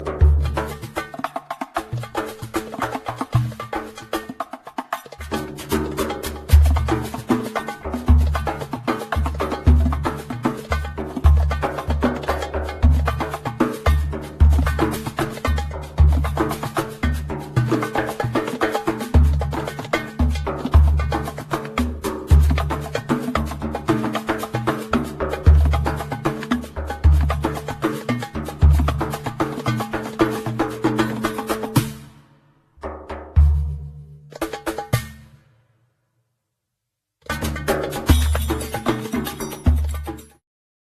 darabuka, tombak, udu